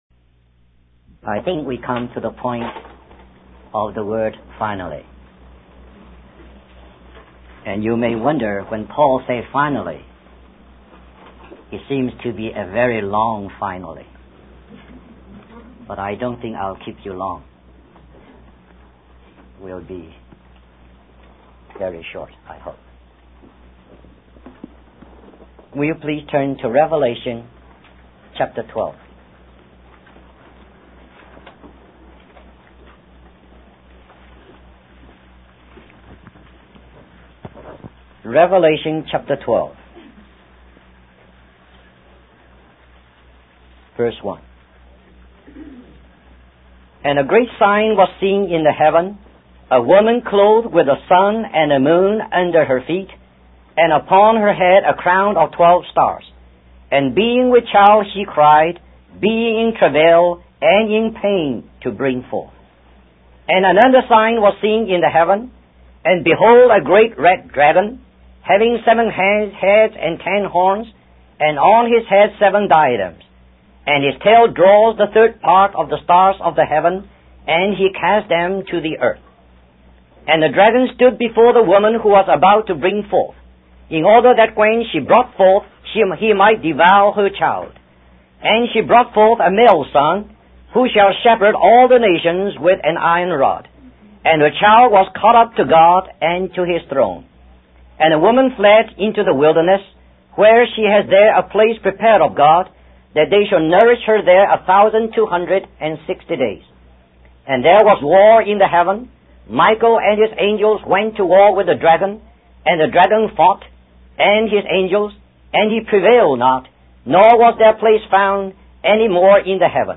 In this sermon, the preacher discusses the testimony of Jesus and its significance in opening the way for the return of the king.